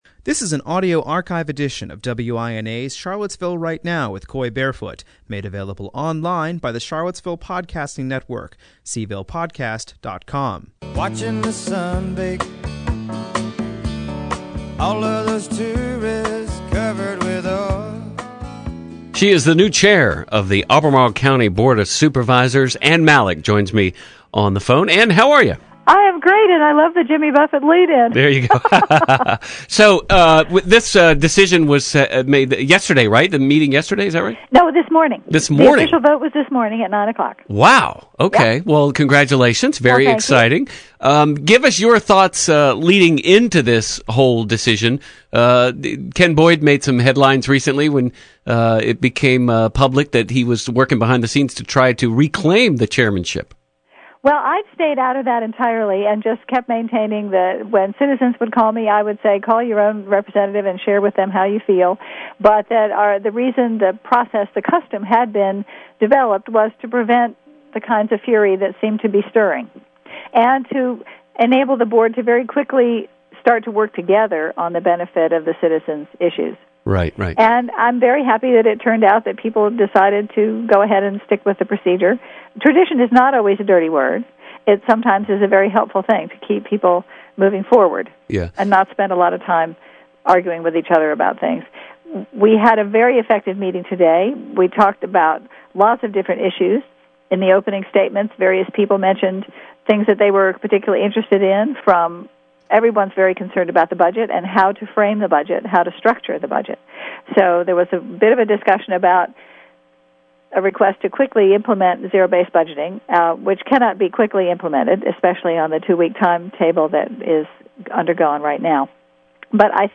The Rivanna Water and Sewer Authority’s Board of Directors hosted the four-way meeting